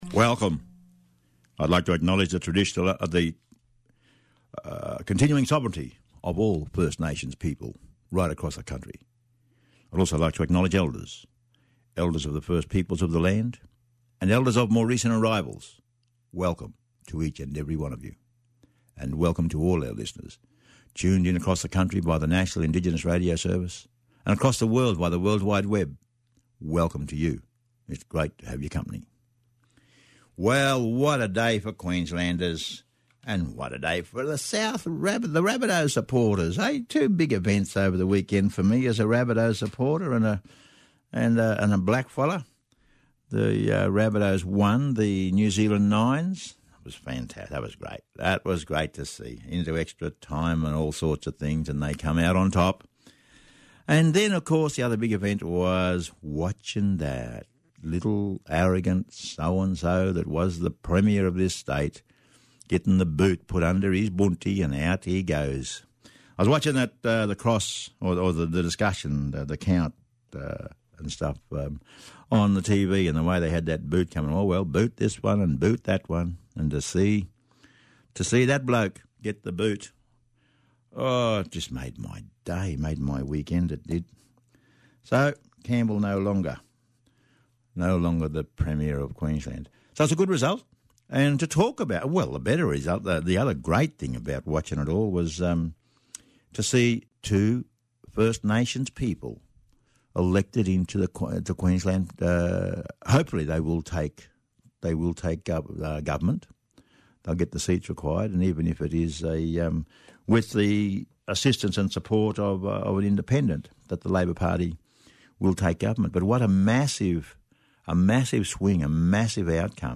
talks to Leanne Enoch and Billy Graham who are the first indigenous parliamentarians elected in Qld for 41 years.